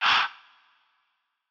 Haah.wav